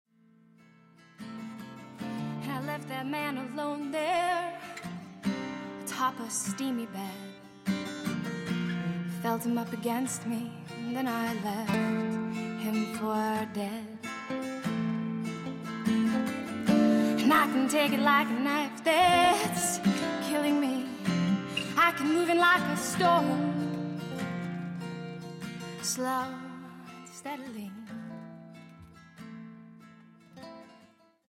Indie/Folk